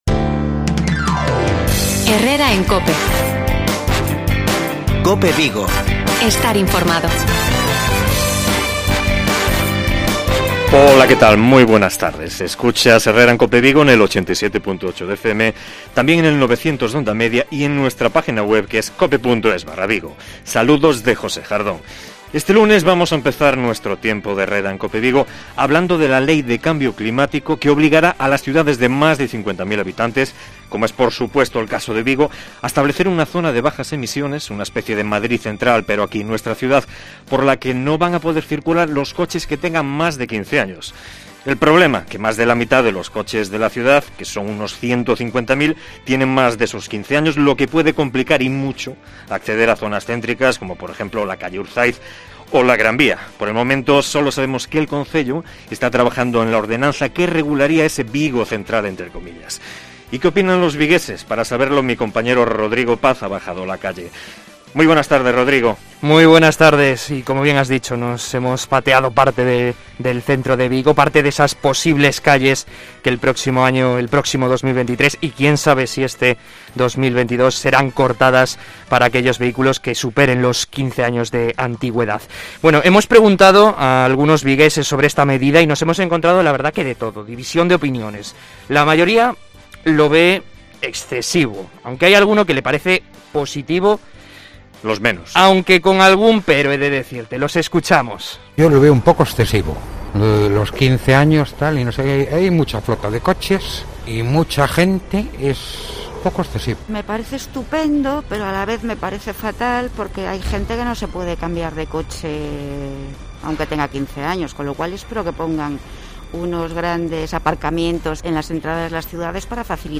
Algunos vigueses opinan sobre el posible 'Vigo Central'